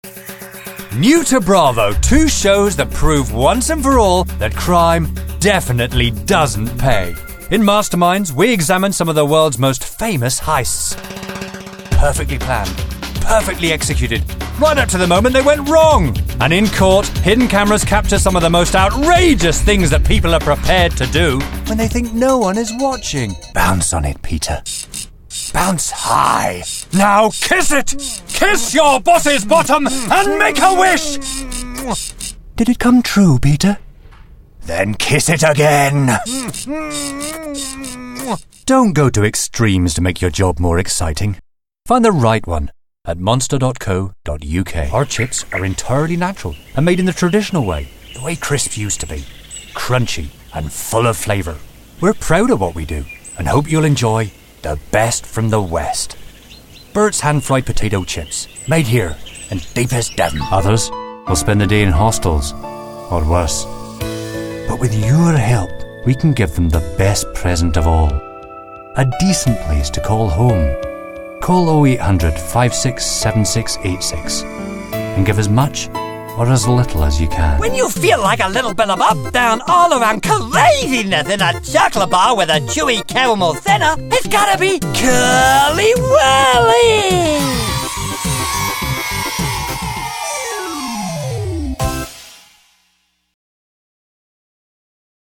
Commercial Showreel
Male
Neutral British
British RP
Confident
Friendly
Relaxed